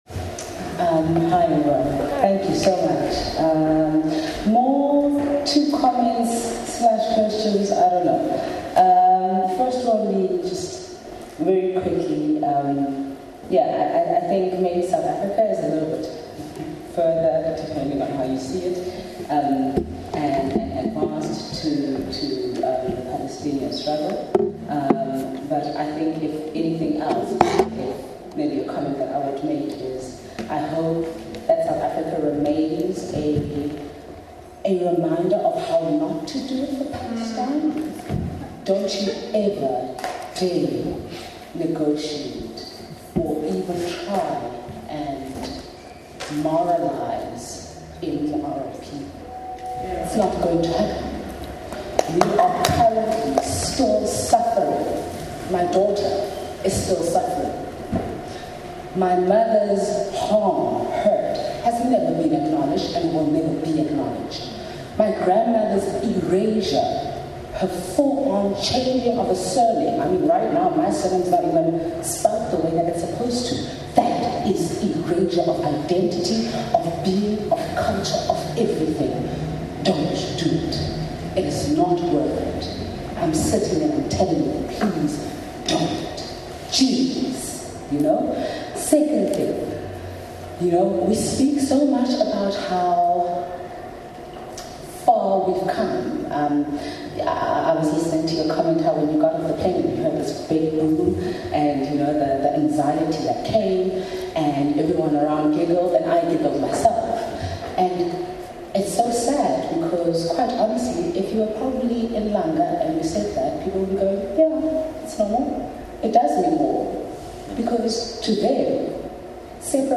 audience homecoming centre, district six | Landwalks Sound Archive / الحق في الأرض: الأرشيف الصوتي
Ambient